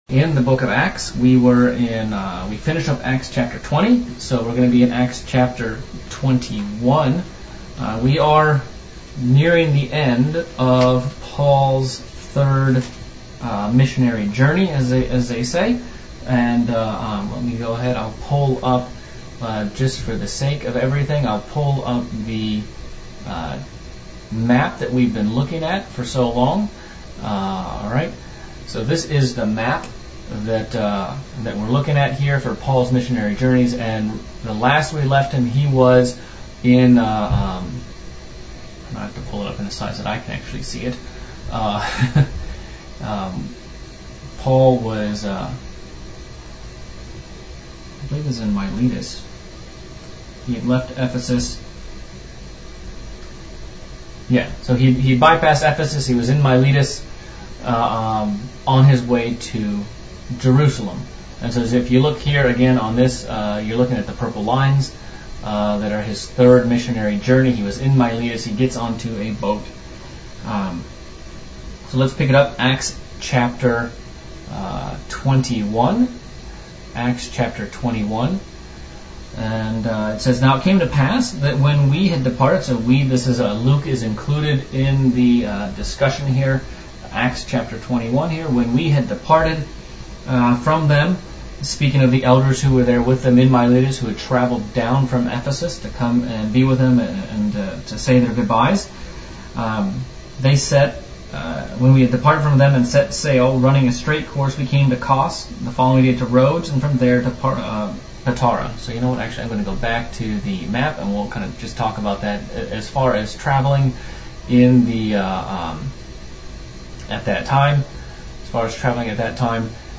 December 2020 Bible Study Acts 21
Given in Central Illinois